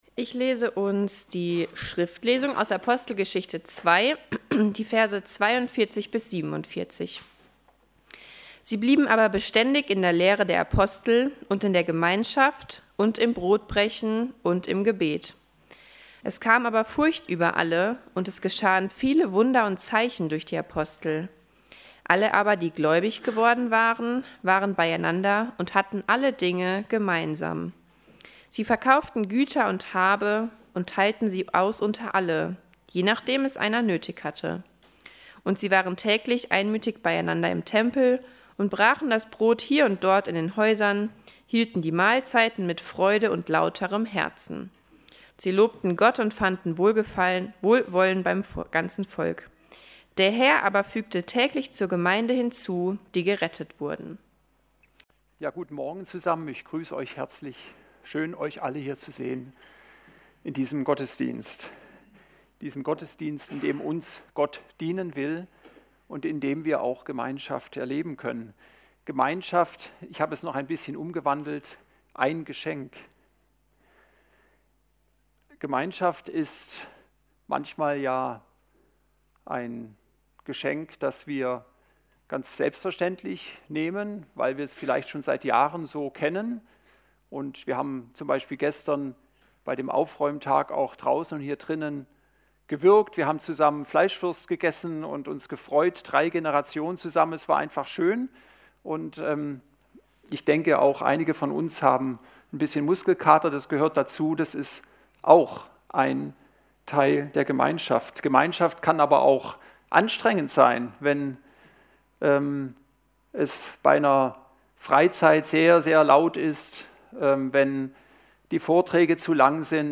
Predigten – Evangelische Gemeinschaft Kredenbach